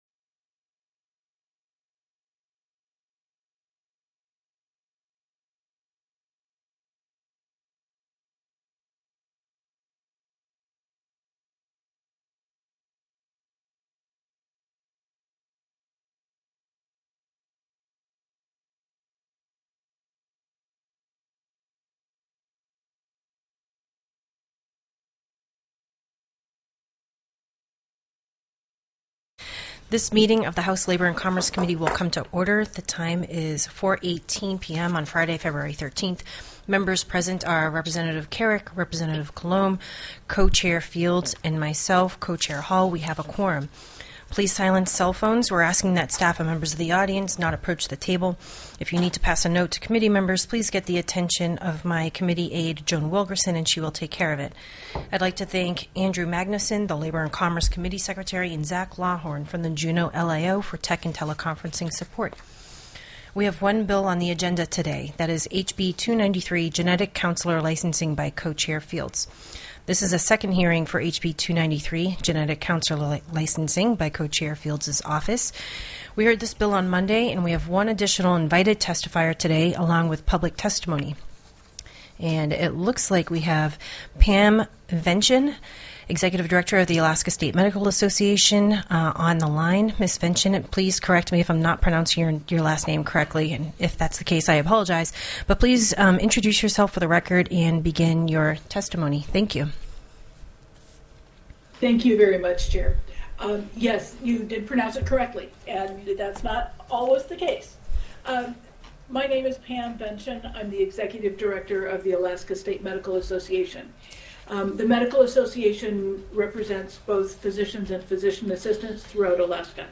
The audio recordings are captured by our records offices as the official record of the meeting and will have more accurate timestamps.
HB 293 GENETIC COUNS: LICENSING; ADVISORY CNCL. TELECONFERENCED Heard & Held -- Public Testimony -- Document Name Date/Time Subjects Open document in new window X